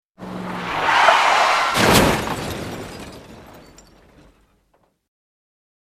シュージャン（95KB）
マルチメディアカード記録済み効果音12種類